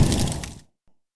battlemage_hit3.wav